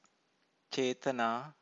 Chethanā